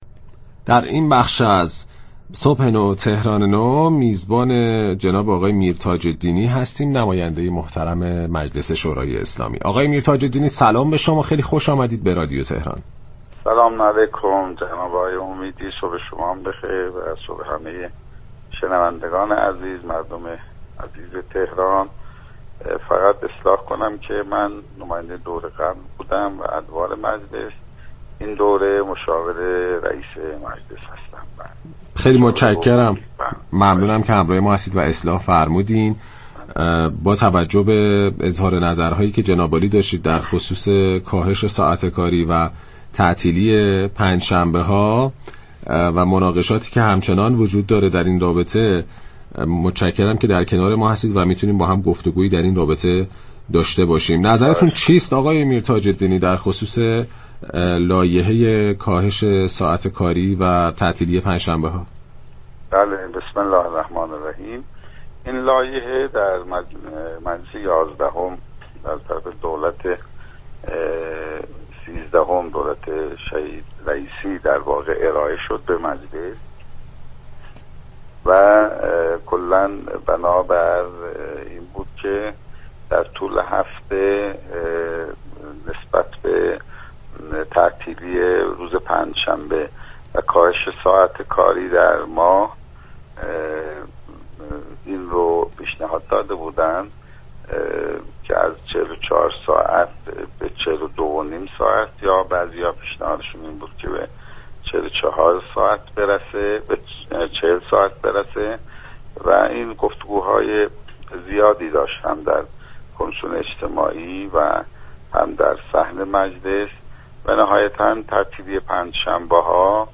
به گزارش پایگاه اطلاع رسانی رادیو تهران، حجت الاسلام سید محمدرضا میرتاج الدینی مشاور رئیس مجلس در گفت و گو با با برنامه «صبح نو، تهران نو» درباره لایحه كاهش ساعت كاری ادارات و تعطیلی پنجشنبه‌ها گفت: این لایحه در مجلس یازدهم و از سوی دولت شهیدرئیسی ارائه شد.